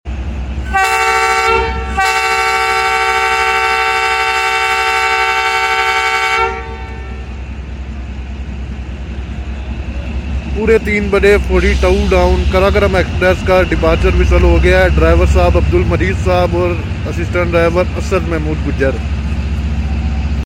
Deprture whistle of 37 up sound effects free download
Deprture whistle of 37 up at Noorshah railway station